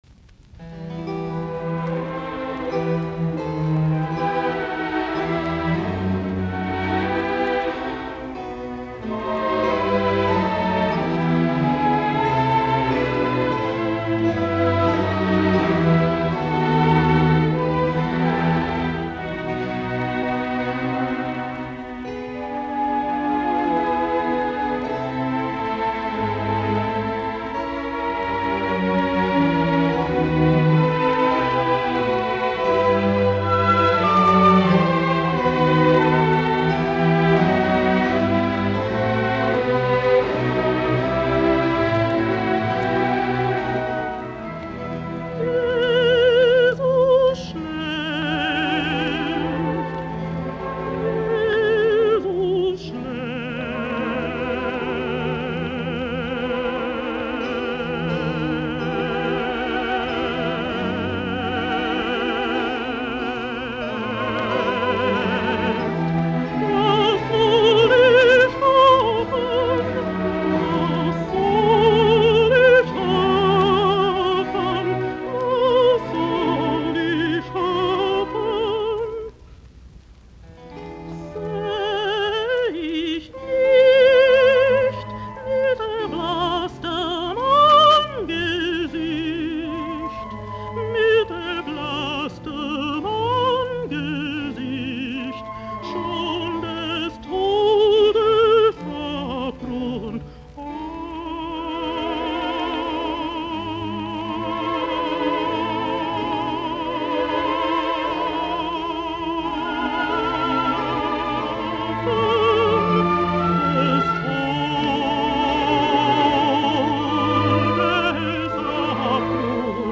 musiikkiäänite
Anderson, Marian ( altto ) Bach arias : and great songs of faith.